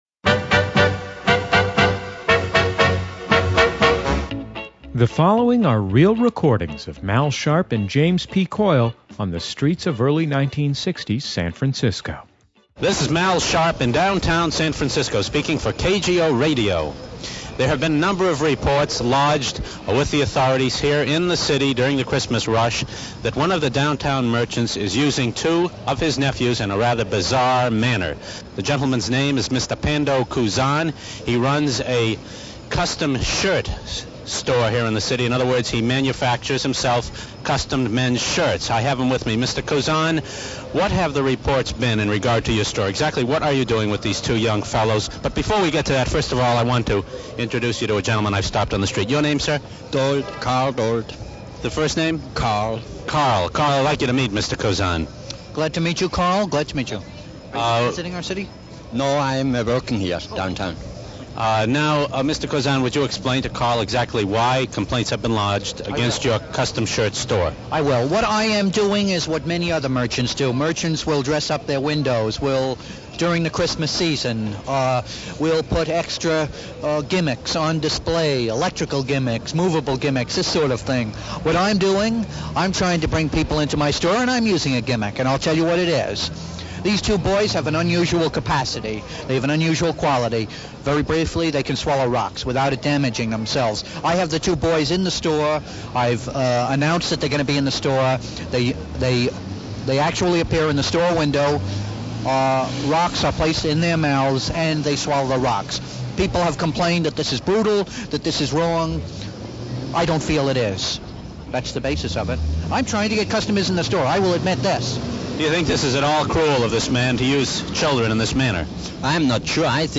From The Coyle & Sharpe Podcast, a custom shirtmaker tries to draw attention to his shop by having children eat rocks in his window.  A real recording from the early 1960s.